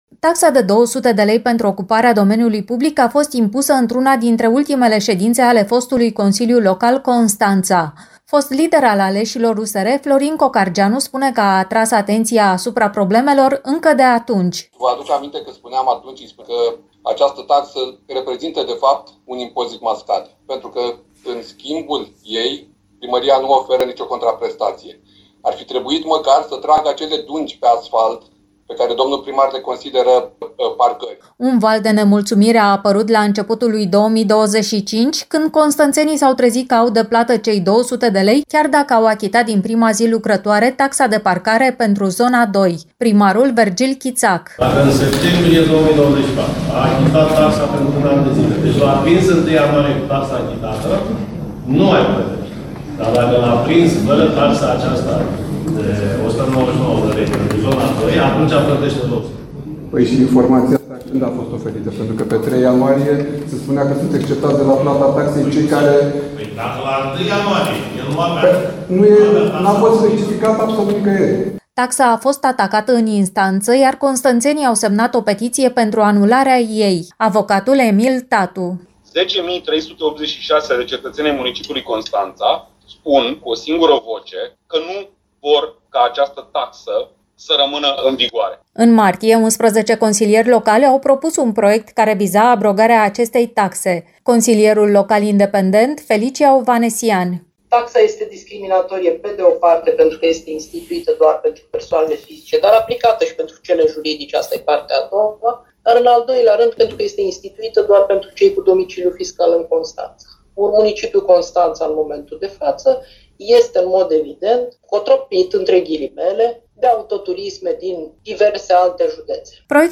Vorbim despre istoricul acestei taxe controversate, încercările de abrogare în Consiliul Local și apoi anularea în instanță, dar și despre reacțiile constănțenilor care au luat cuvântul în ședințele extraordinare, în următorul reportaj